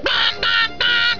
Pingo, Pingu's friend, talking